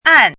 àn
拼音： àn
注音： ㄢˋ